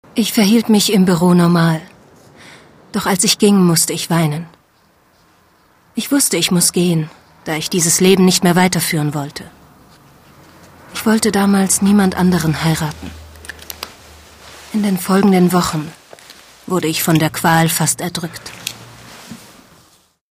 deutsche Sprecherin mit einer warmen, sinnlichen, kraftvollen, wandelbaren Stimme.
Sprechprobe: Industrie (Muttersprache):